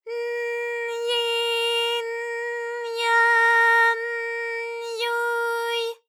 ALYS-DB-001-JPN - First Japanese UTAU vocal library of ALYS.
y_n_yi_n_ya_n_yu_y.wav